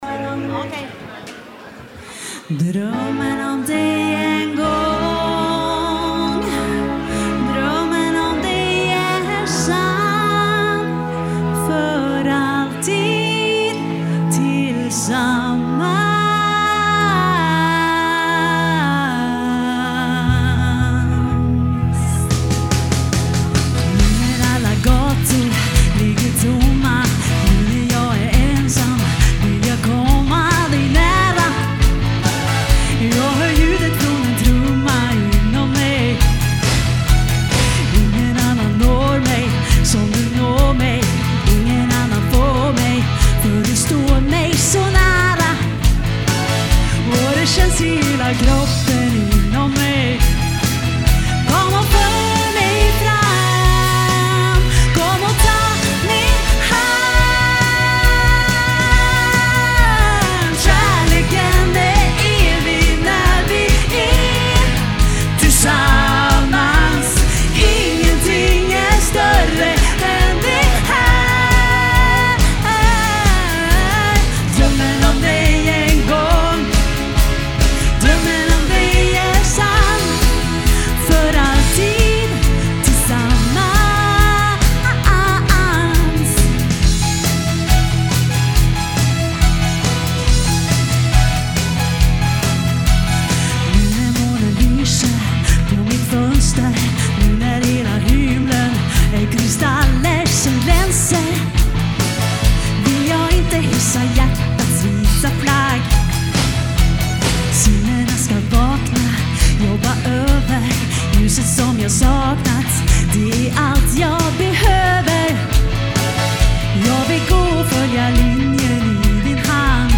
sång
trummor
keyboards
gitarr
Alltid 100% live!
• Coverband